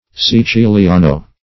Search Result for " siciliano" : The Collaborative International Dictionary of English v.0.48: Siciliano \Si*ci`li*a"no\, n. [It., Sicilian.]